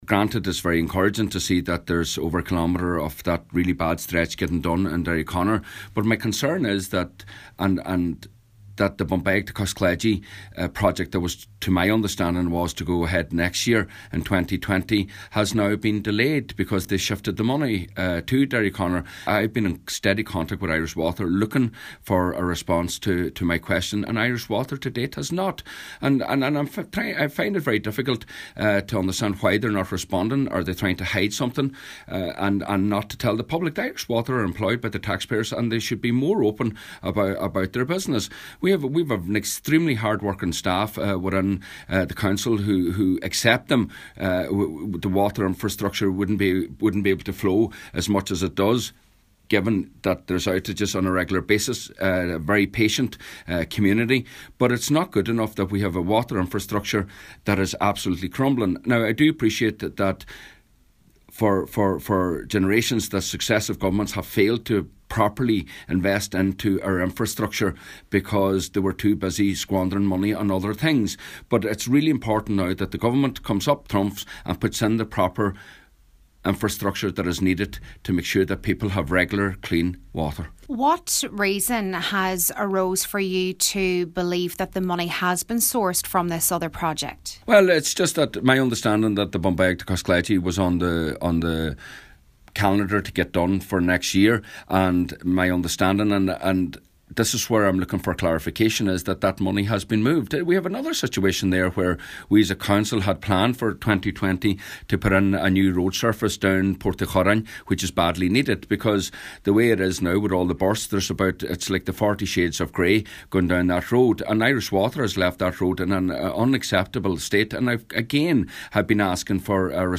He says while work in Derryconnor is vital, other planned infrastructure cannot afford to suffer either: